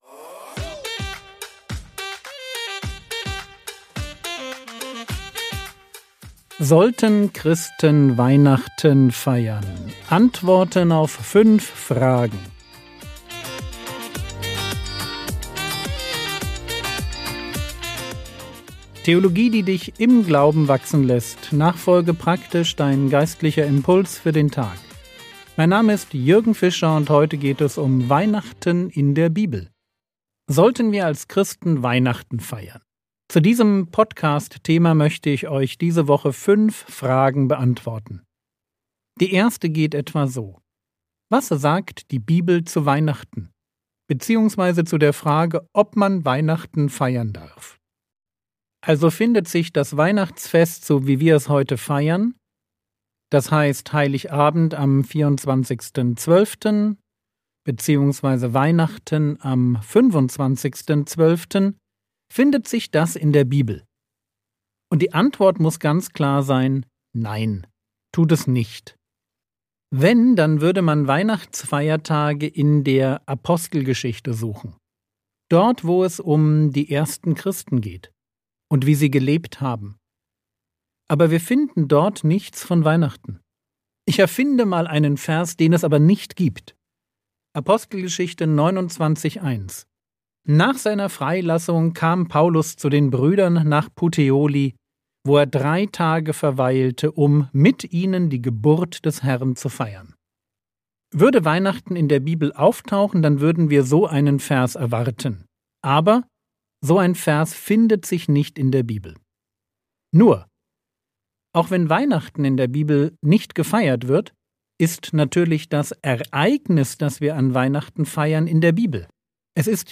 Christen und Weihnachten (1/5) ~ Frogwords Mini-Predigt Podcast